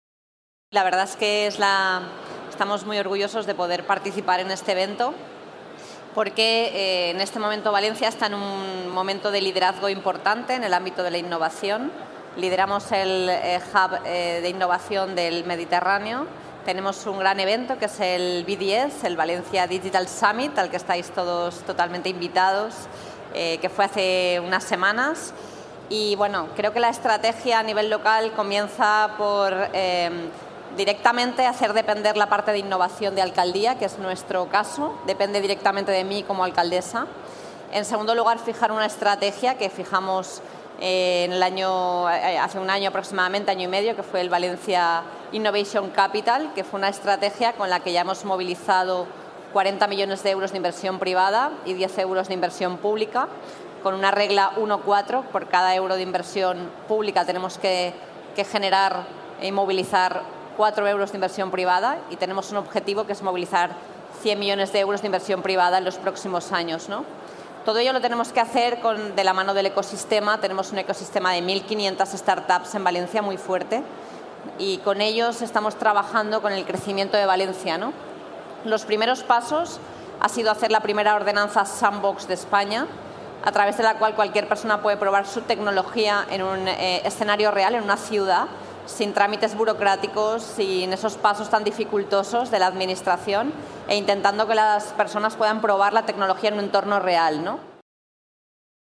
• María José Catalá ha anunciado el proyecto en la Web Summit de Lisboa.